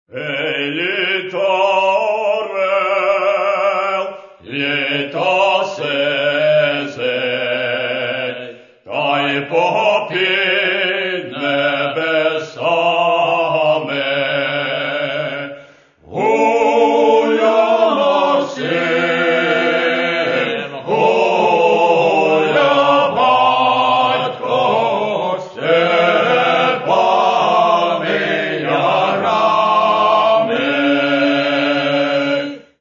Каталог -> Народная -> Аутентичное исполнение